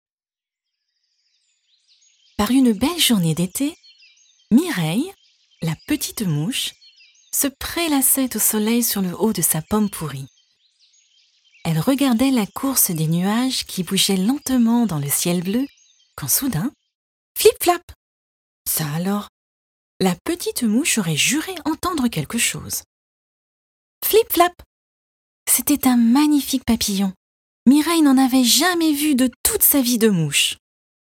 Voix off allemand